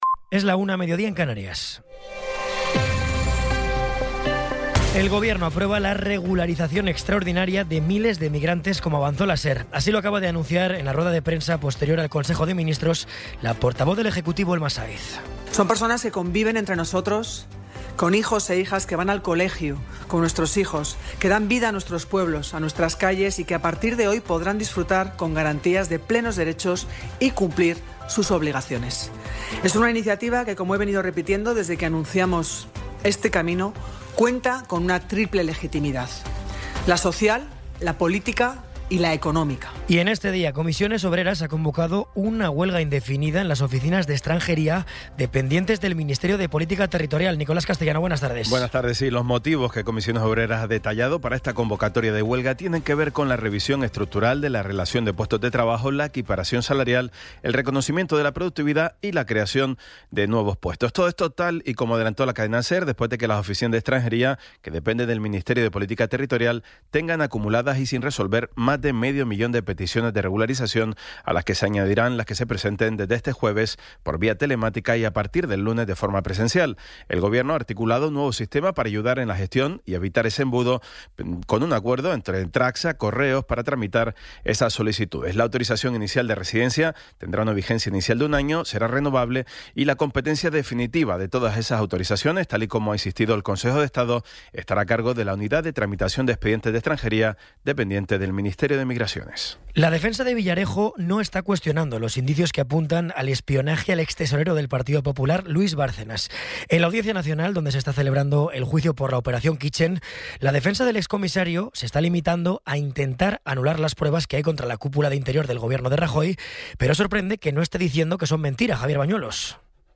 Resumen informativo con las noticias más destacadas del 14 de abril de 2026 a la una de la tarde.